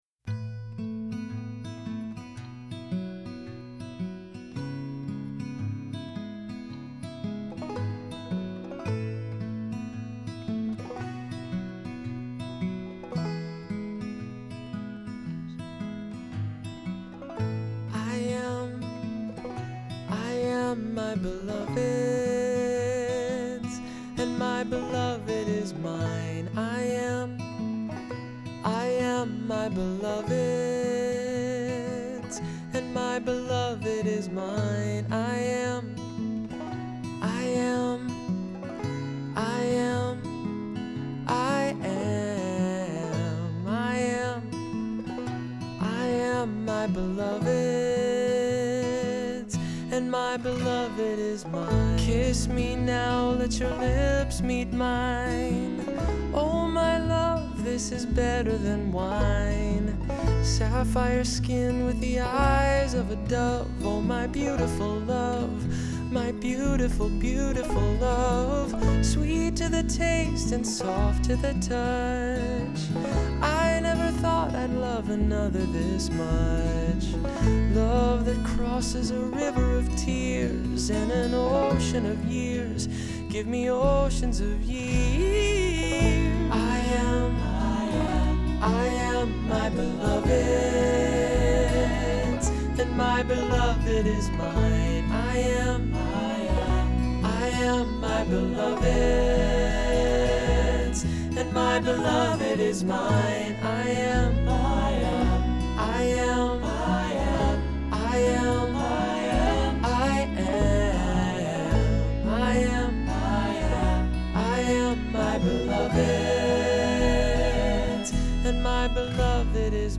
Voicing: SAB; Three-part equal; Three-part mixed; Assembly